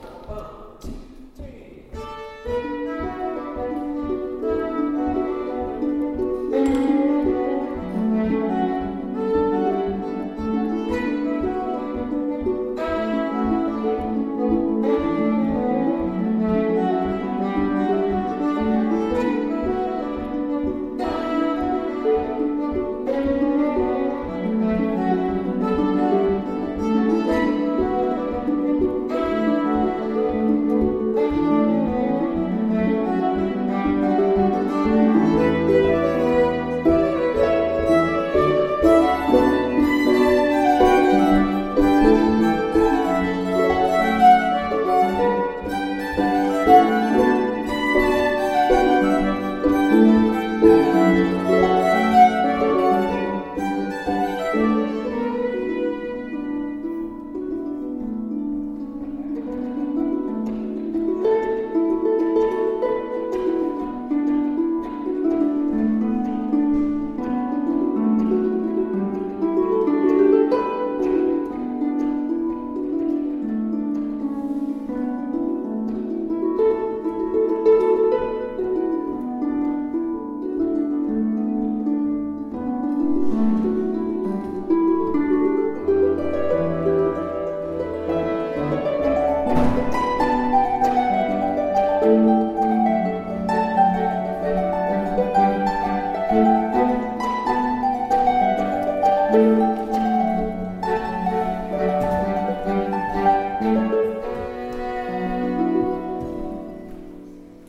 Workshop October 2025